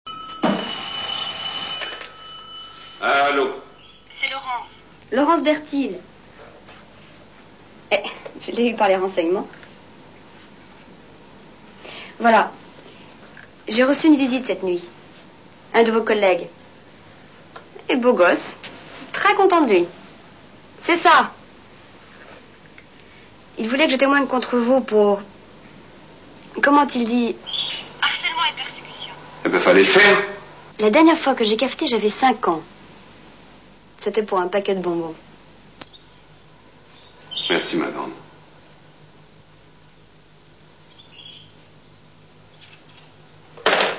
Nous vous offrons en complète EXCLUSIVITE, les dialogues du films en MP3 où Dorothée est présente.
Dorothée et Philippe Noiret (2)       : 0 mn 43 (170kb)